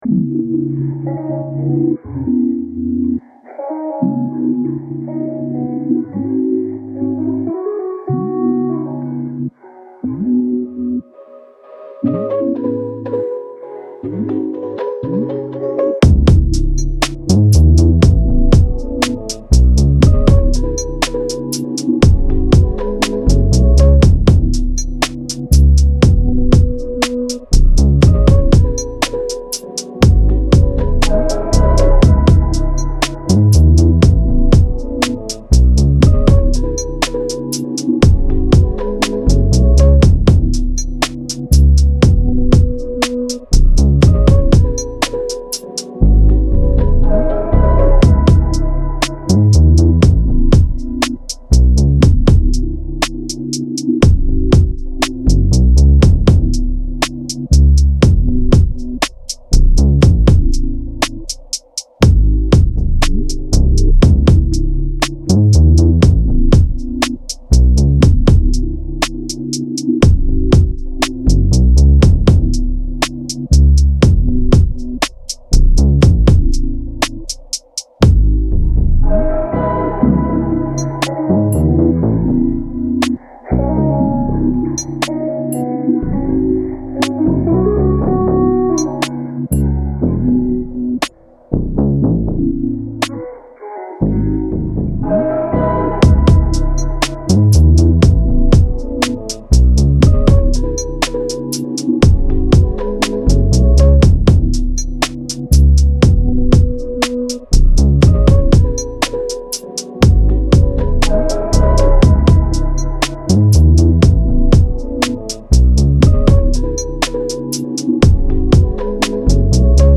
R&B
B Major